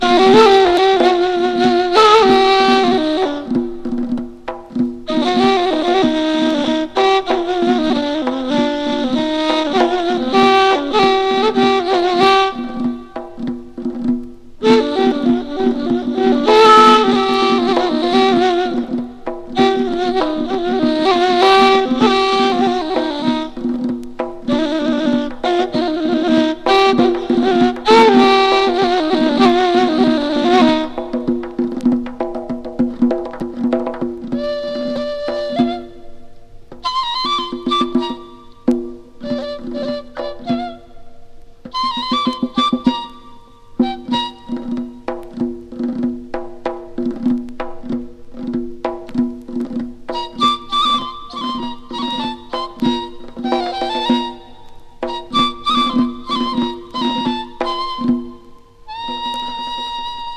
楽器の被りが少ないので、サンプリングソースにもいかがでしょうか！